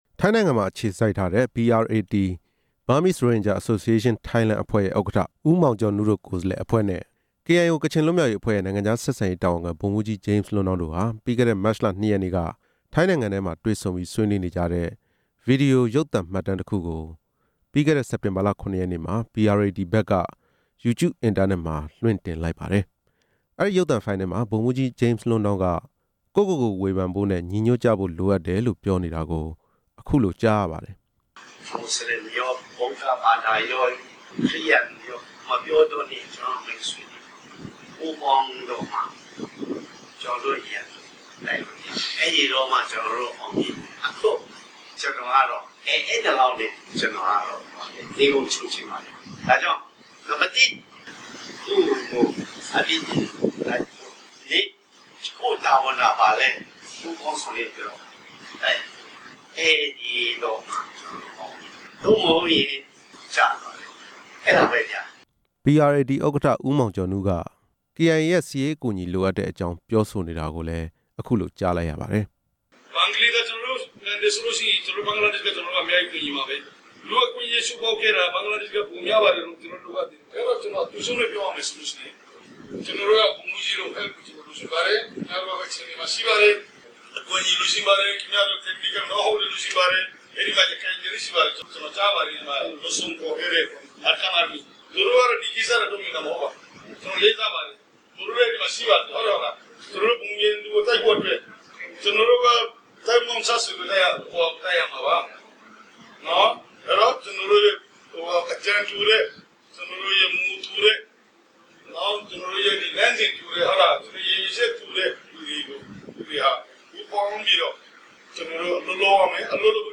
ဗမာ-ရိုဟင်ဂျာအသင်း ဆွေးနွေးပွဲ တင်ပြချက်